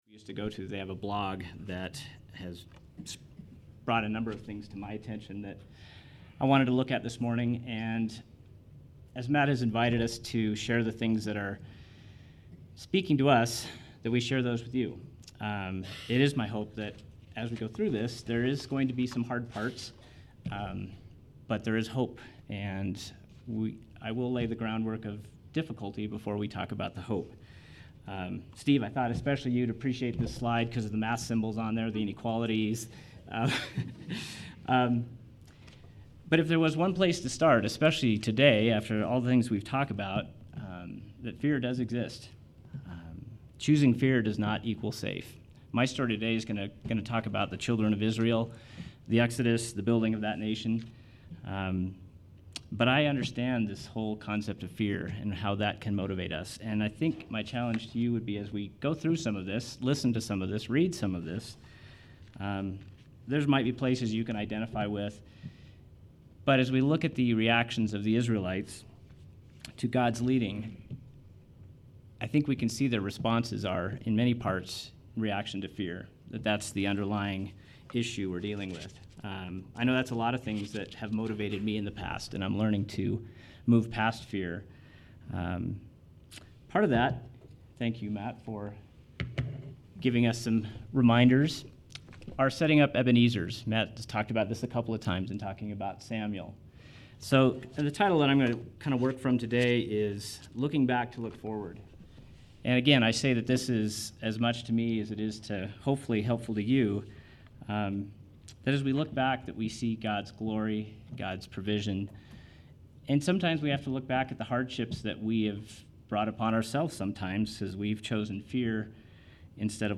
message from the past Sunday on the role of fear and hope in our lives.